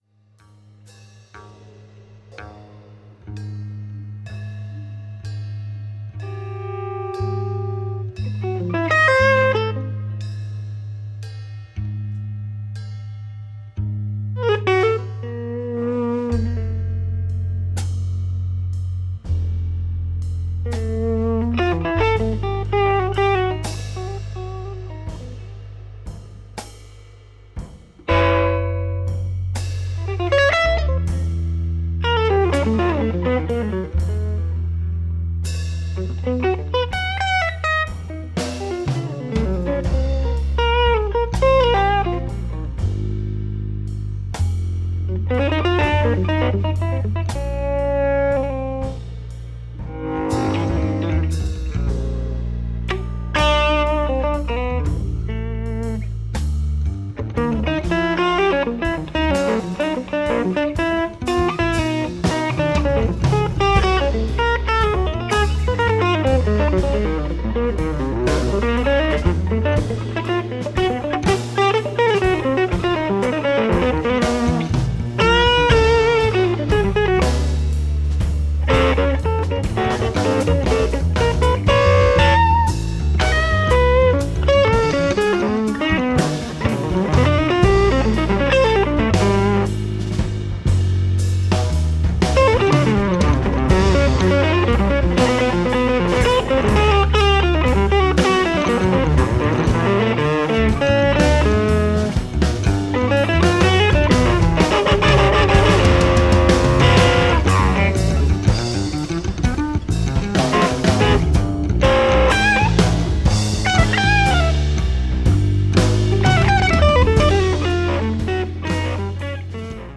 electric guitar
double bass
drums
trumpet
saxophones
trombone
Recorded live at Le Triton, Les Lilas, France